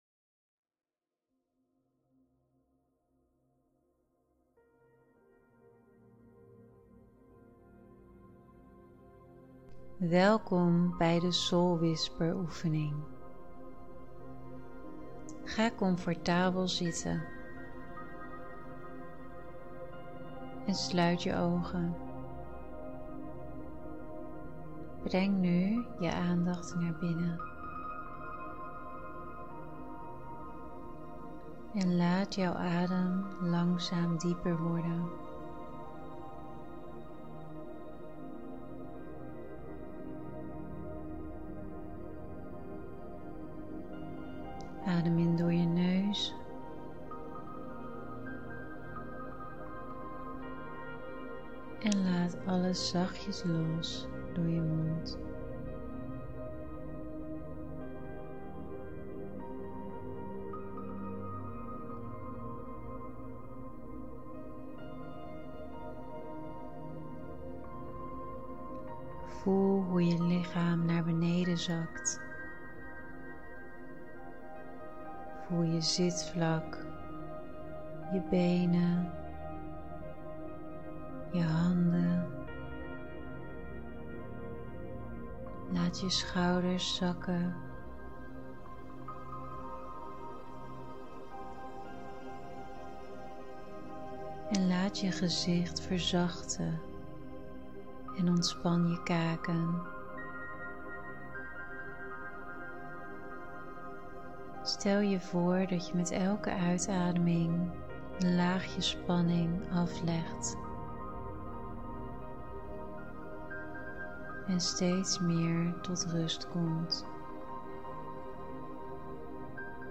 ~ Videoles ~ ~ Soul Whisper Oefening ~ In deze begeleide oefening ga je leren luisteren naar de fluistering van je Ziel.
Soul+Whisper+-+oefening-6bbadc39.m4a